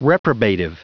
Prononciation du mot : reprobative